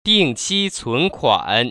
[dìngqī cúnkuăn] 딩치춘쿠안  ▶